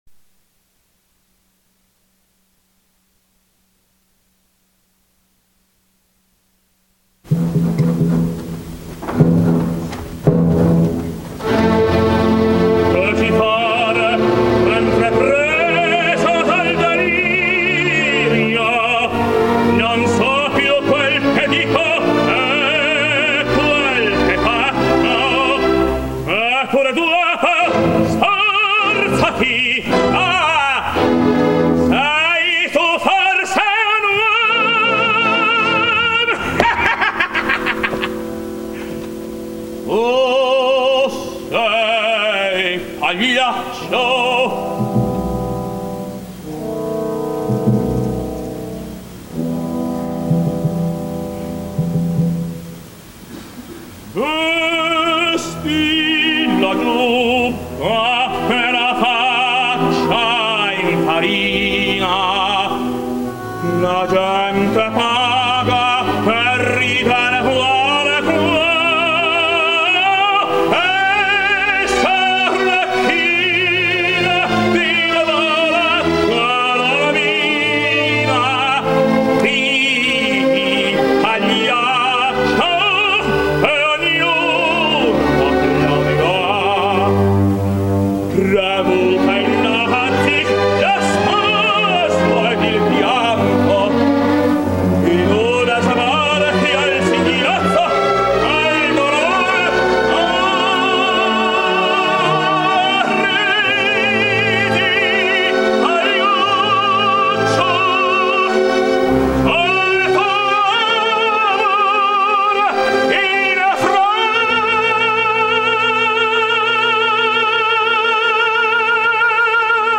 ｲﾀﾘｱ歌劇
ﾃﾉｰﾙ
1961年ｲﾀﾘｱ歌劇日本公演